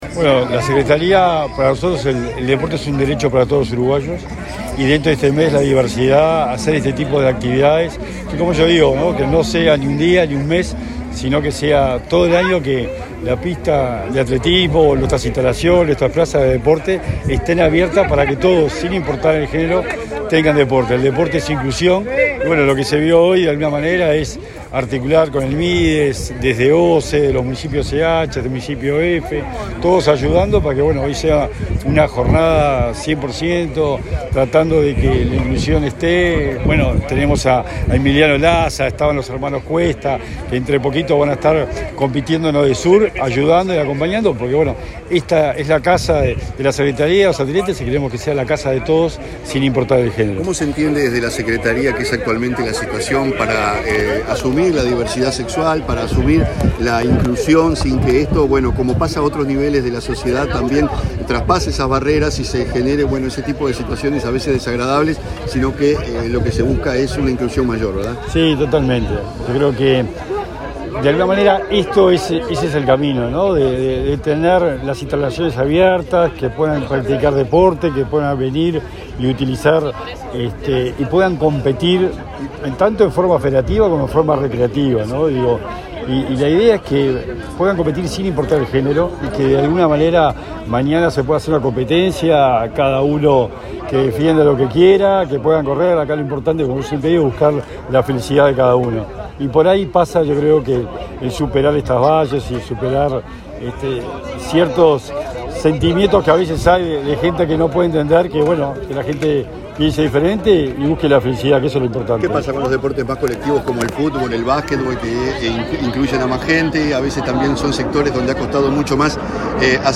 Declaraciones del secretario nacional del Deporte
Este viernes 16 en la pista de atletismo, el secretario nacional del Deporte, Sebastián Bauzá, participó en una actividad organizada por el Ministerio de Desarrollo Social junto con la Secretaría Nacional del Deporte, el Municipio CH y diferentes entidades del deporte amateur y profesional, con la que se procura generar un espacio de no discriminación en el deporte. Luego dialogó con la prensa.